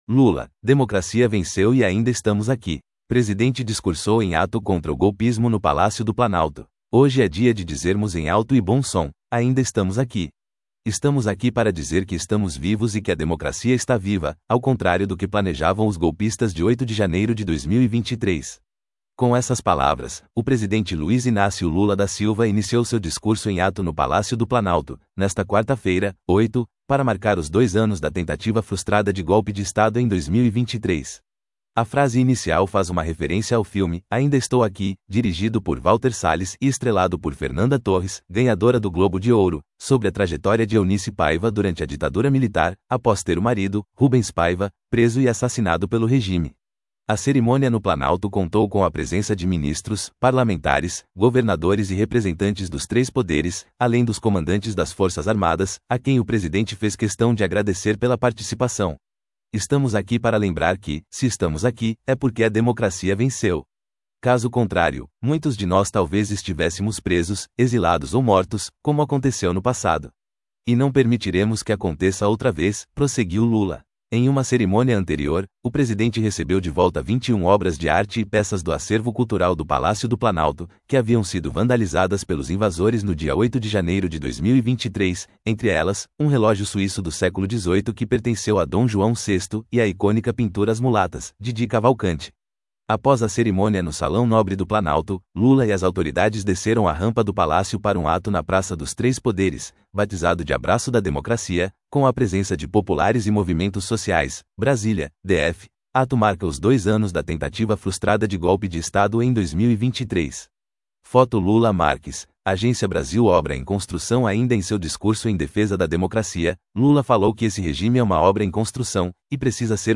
Audio version of Lula celebra a democracia em ato pelos dois anos do 8 de janeiro